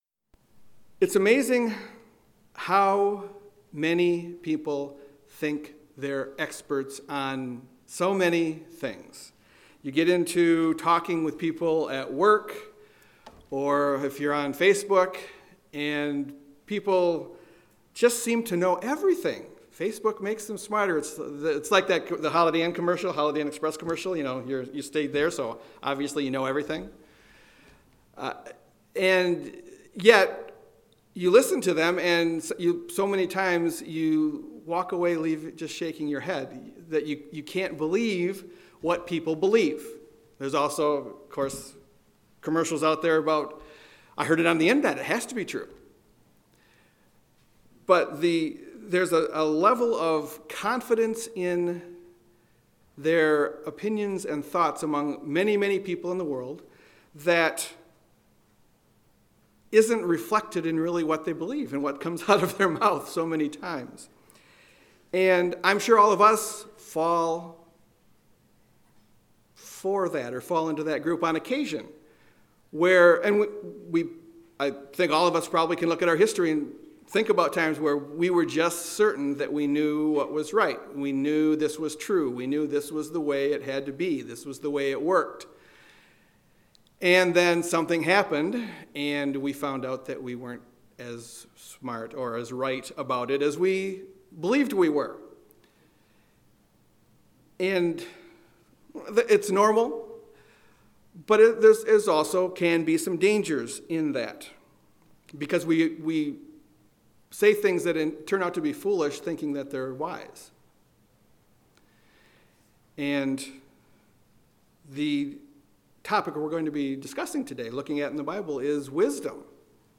Given in Grand Rapids, MI